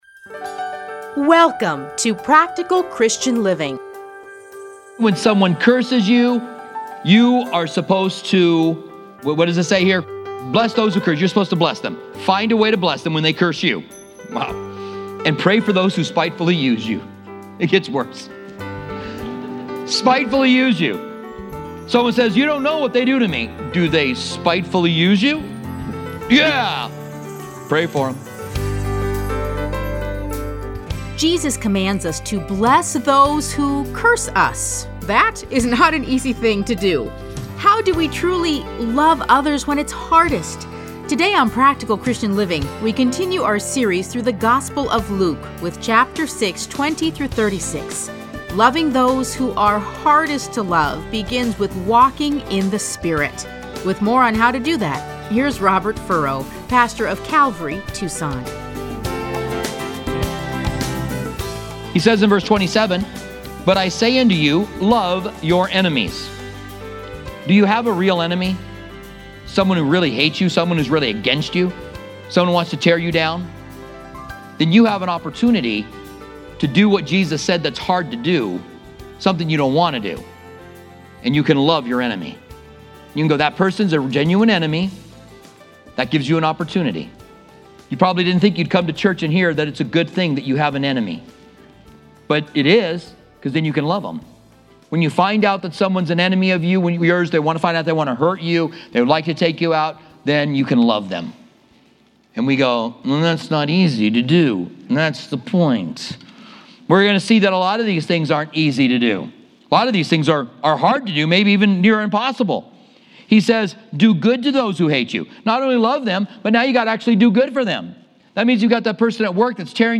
Listen to a teaching from Luke 6:20-36.